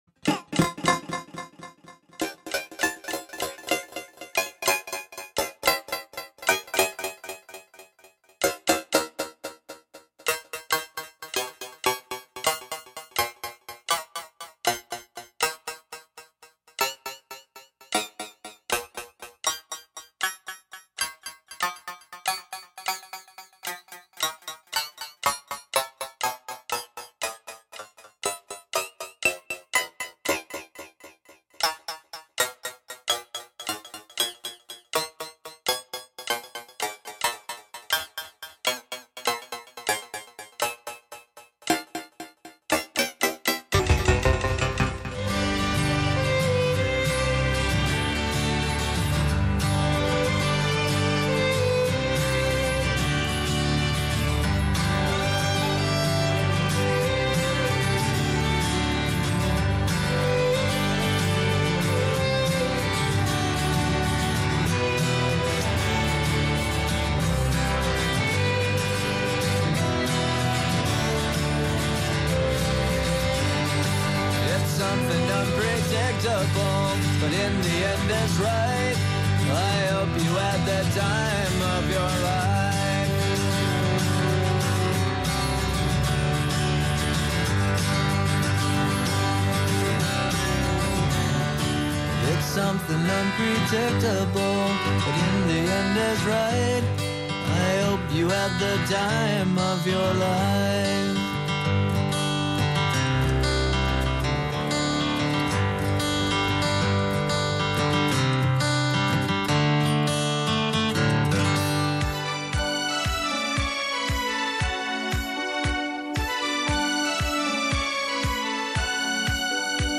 Live from Brooklyn, New York,
making instant techno 90 percent of the time
play those S's, T's and K's like a drum machine